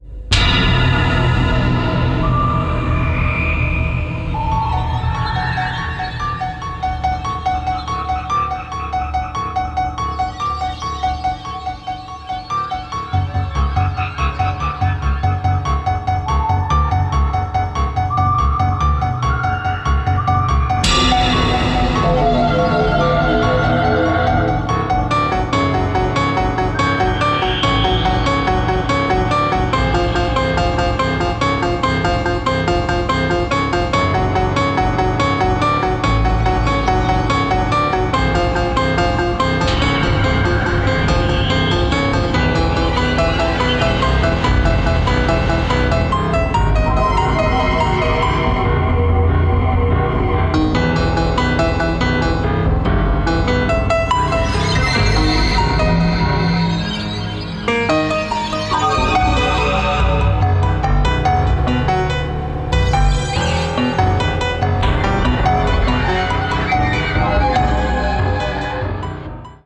Using the Synclavier